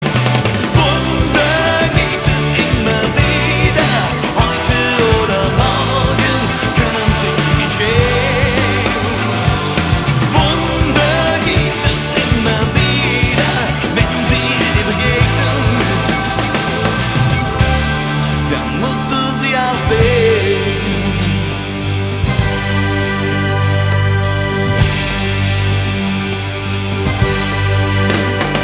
Besetzung: Blasorchester
Tonart: F-Dur